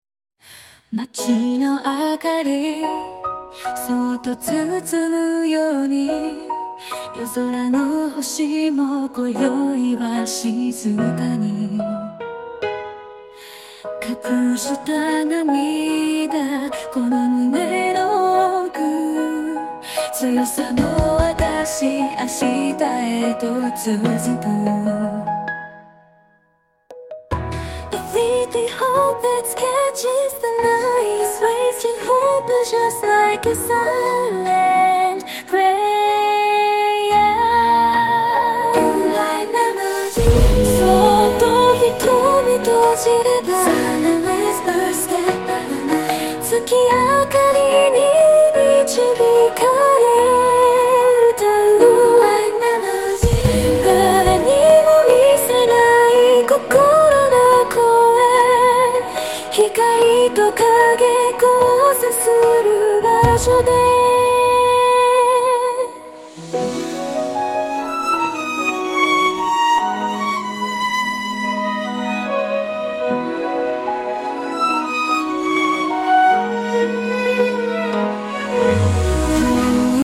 音楽生成AI”Suno AI”がエグすぎ！